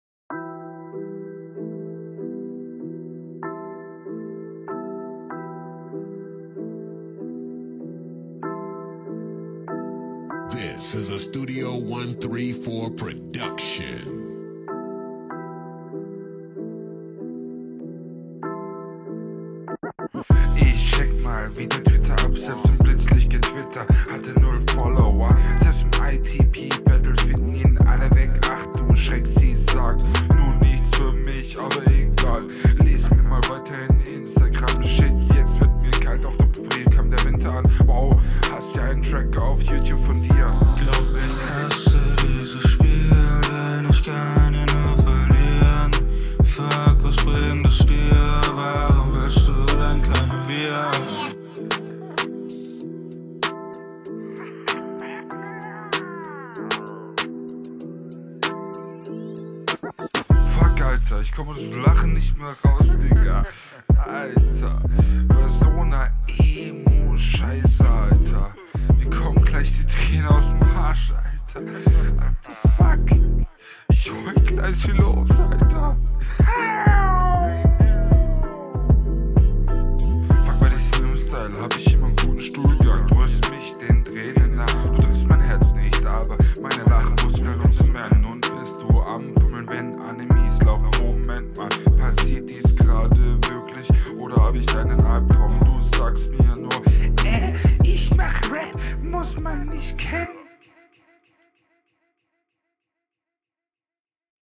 Das selbe Spiel.. deine Reime sind übelst wierd gesetzt und dein Flow ist der holprig …